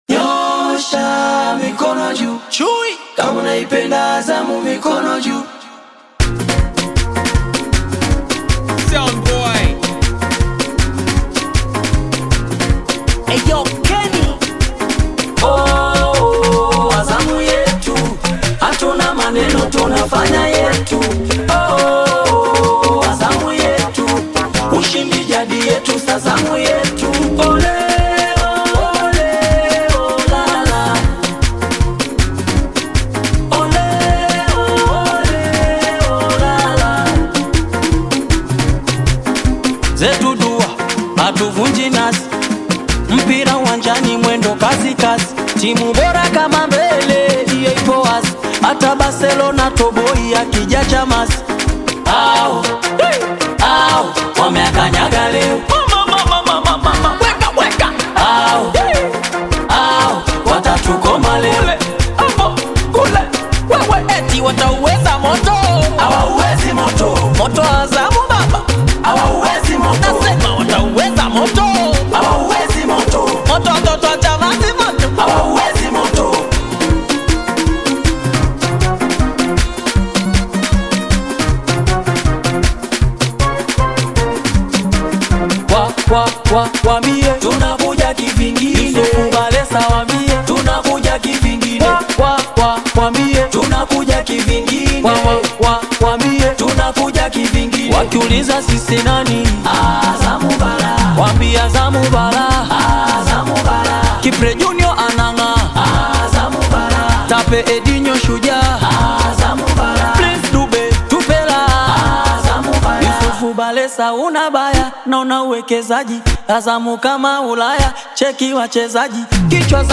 Tanzanian bongo flava artist